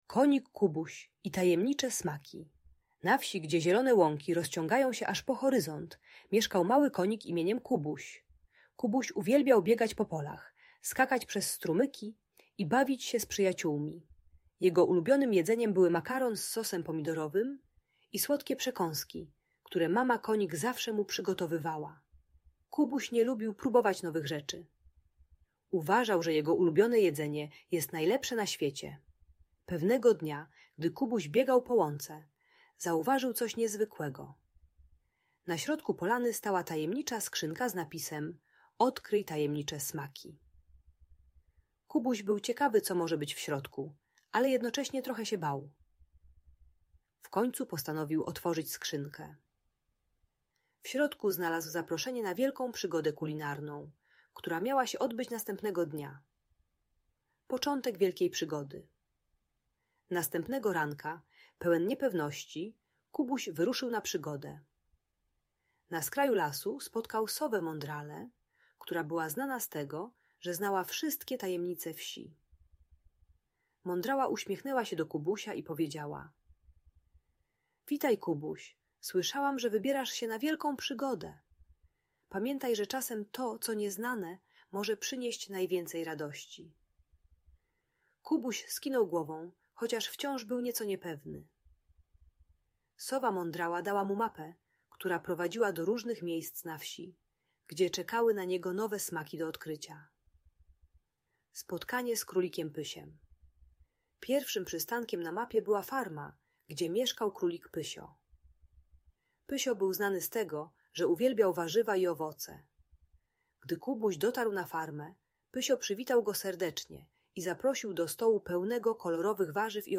Przygody Konika Kubusia - historia o odkrywaniu smaków - Audiobajka